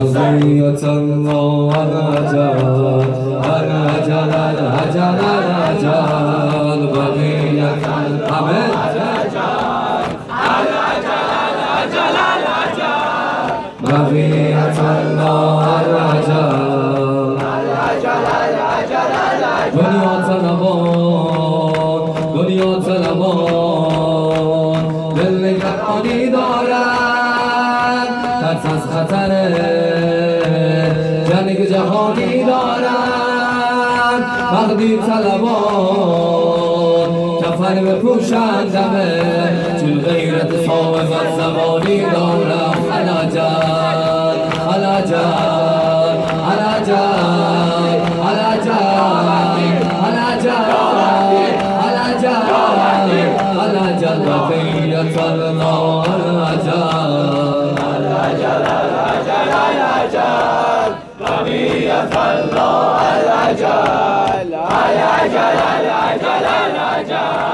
شب اول محرم ۹۷ هیئت صادقیون(ع)